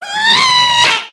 Minecraft Version Minecraft Version snapshot Latest Release | Latest Snapshot snapshot / assets / minecraft / sounds / mob / ghast / scream2.ogg Compare With Compare With Latest Release | Latest Snapshot
scream2.ogg